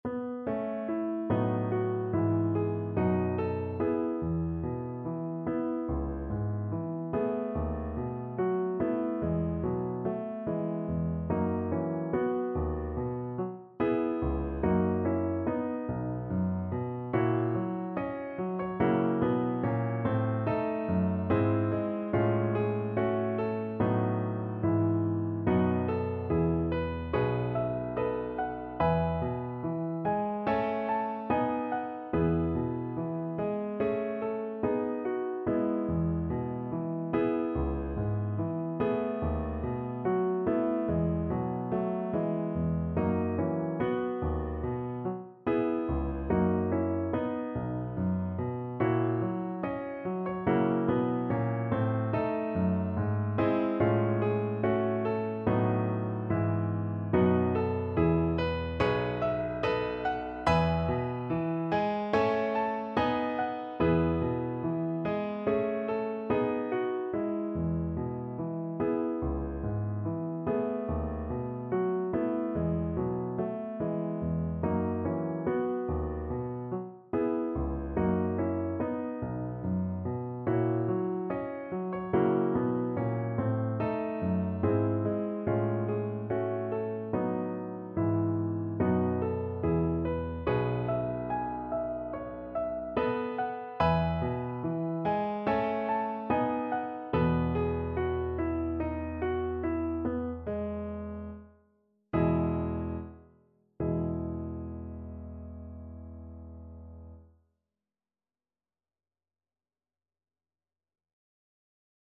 4/4 (View more 4/4 Music)
Classical (View more Classical Voice Music)